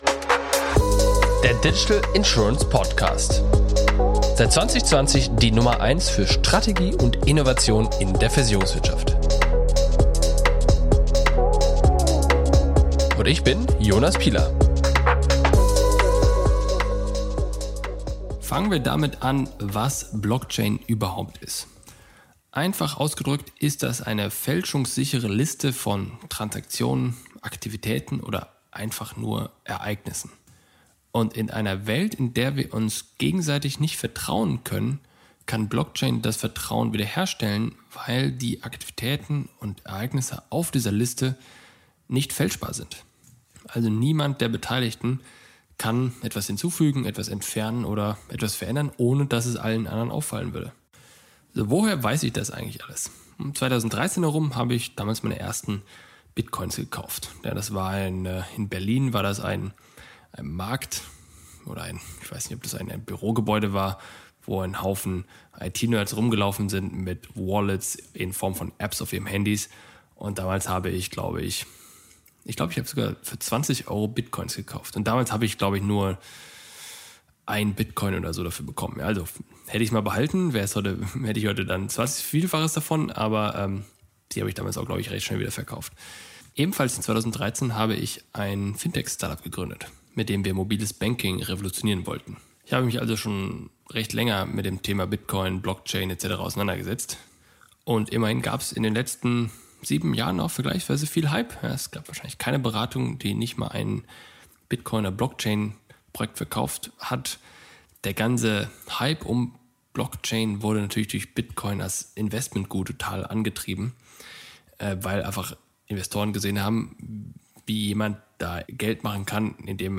Welches grundlegende Prinzip unterliegt der Blockchain, das für die Versicherungswirtschaft in Deutschland nicht relevant ist? monolog